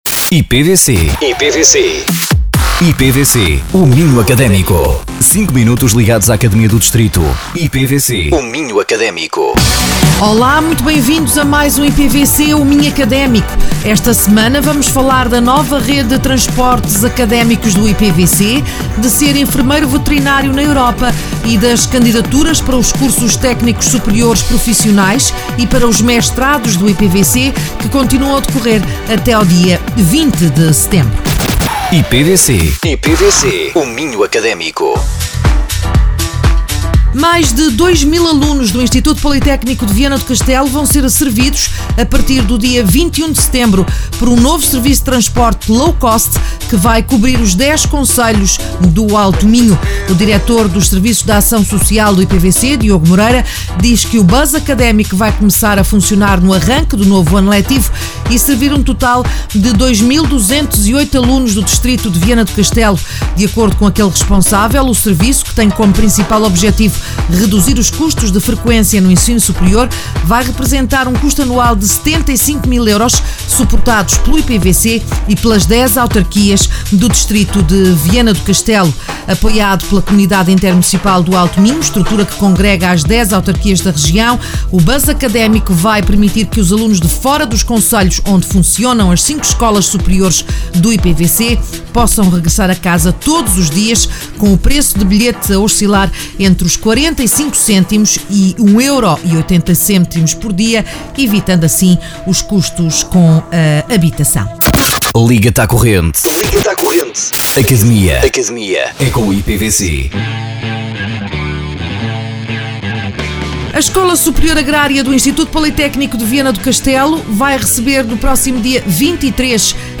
O programa é transmitido todas as quartas-feiras às 11h00, 13h00 e 17h00 e aos domingos às 14h00 e às 20h00.
Entrevistados: